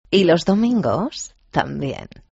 pone una voz muy sensual